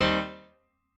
admin-leaf-alice-in-misanthrope/piano34_6_002.ogg at a8990f1ad740036f9d250f3aceaad8c816b20b54